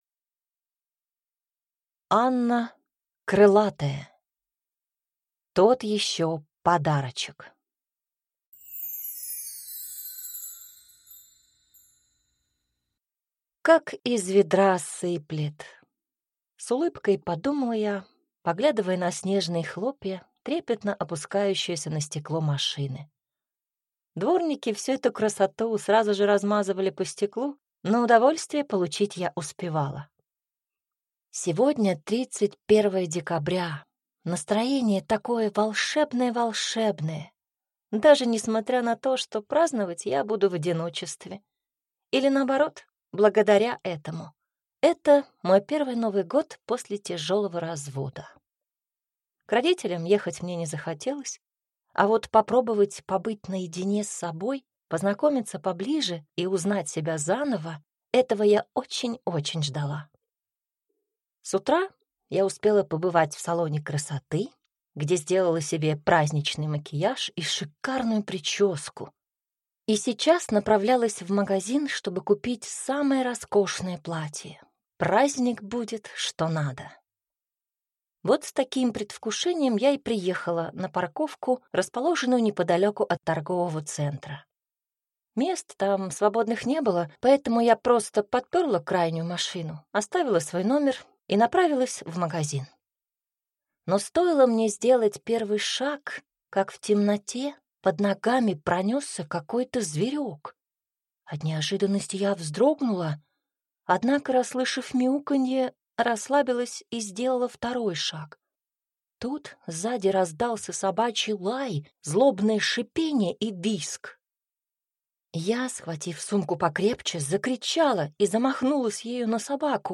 Аудиокнига Тот ещё Подарочек | Библиотека аудиокниг